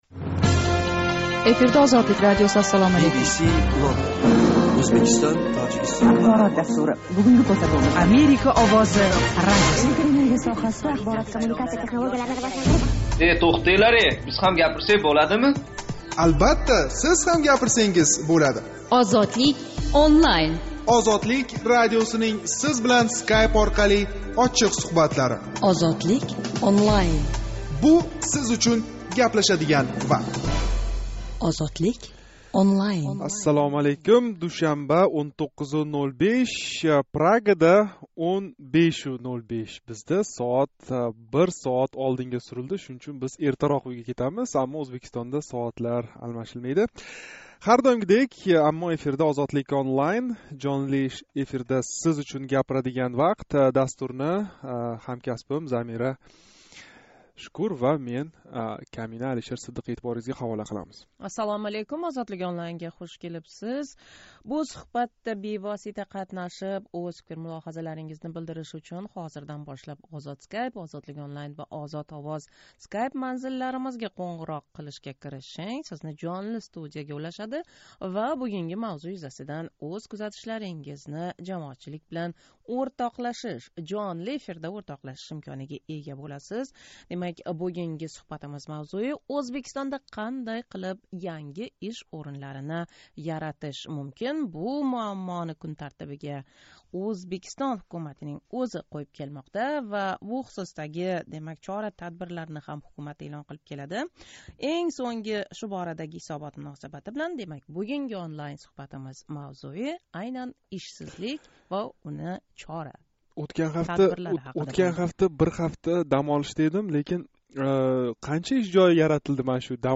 Ўзбекистон шароитида меҳнатга лаëқатли аҳолини фаровон яшашга етарли даромад билан таъминловчи иш ўринларини ким¸ қандай яратиши керак? Душанба кунги жонли суҳбатимизда шу ҳақда гаплашамиз.